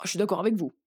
VO_ALL_Interjection_19.ogg